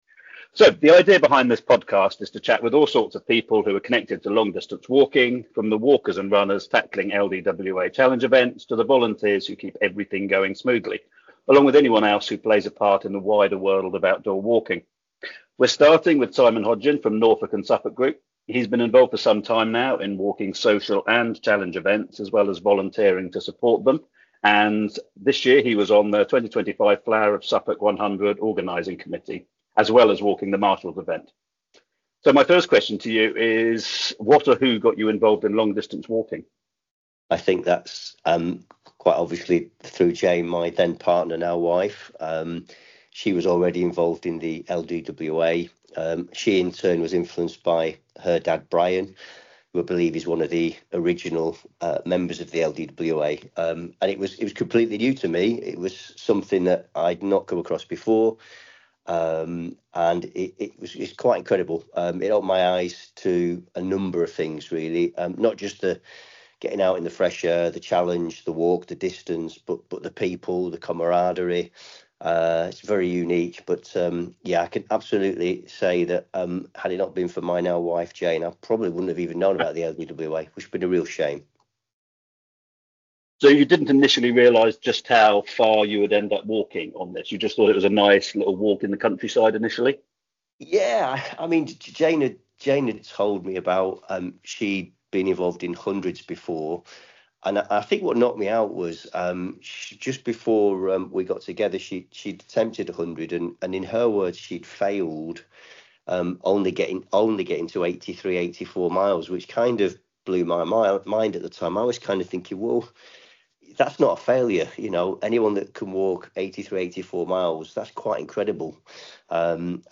Something new for 2026 is a series of podcasts that I’m planning for the LDWA. It’s going to take a fair few podcasts to start getting the editing quality sorted, but I have to start so…